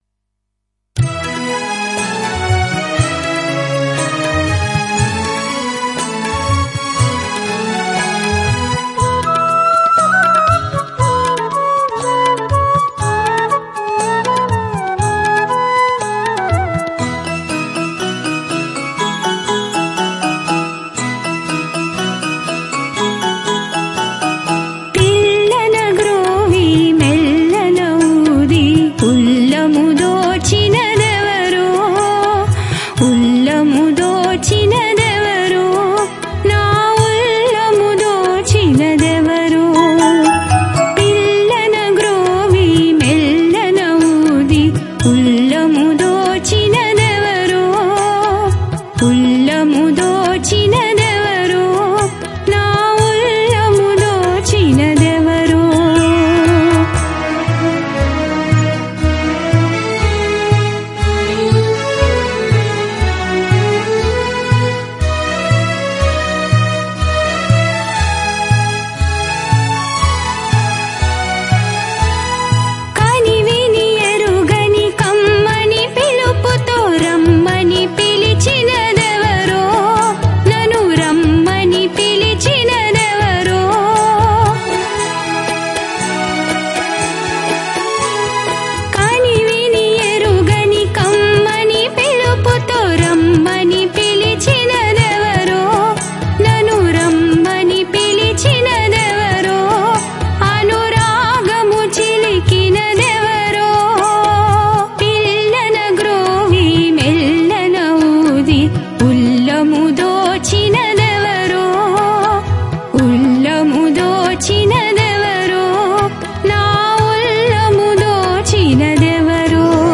Ragam - sankarabharanam